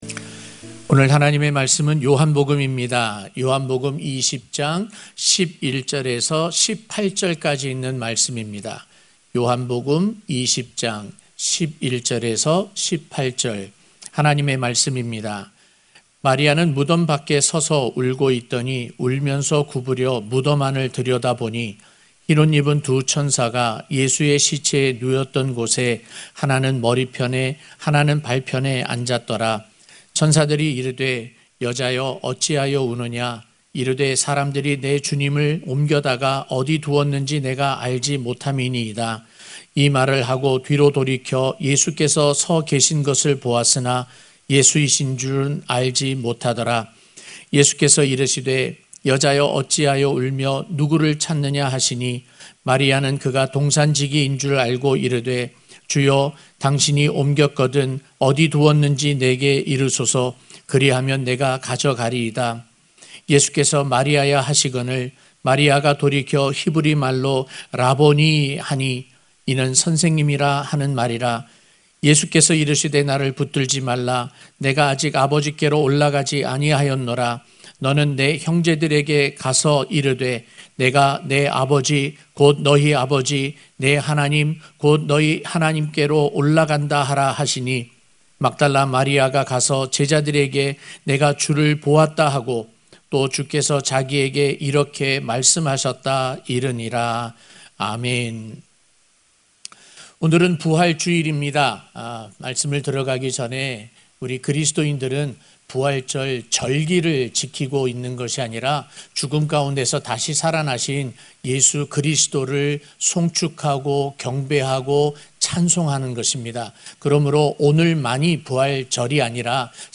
부활주일설교-사랑 이야기 (요 20:11-18)